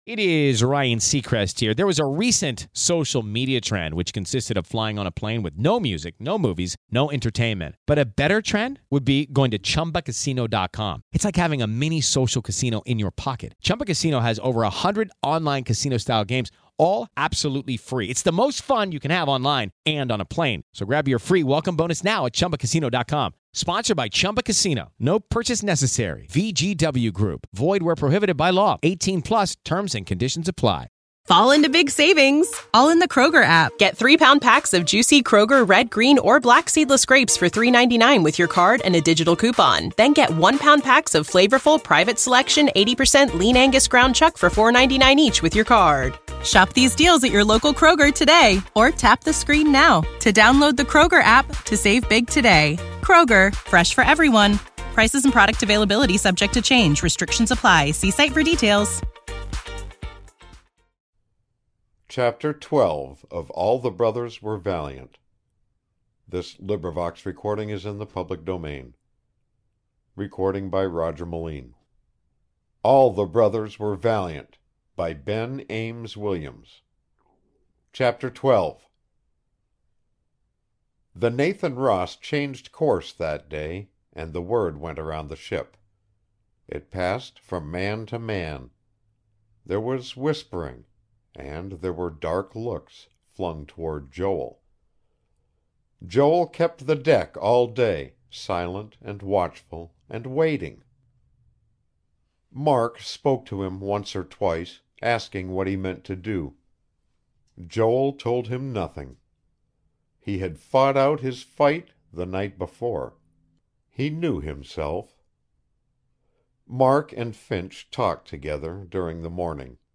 100 Great Audiobooks of Literary Masterpieces!